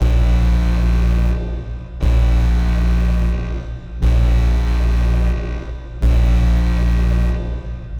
Alarm_Freezeroom.wav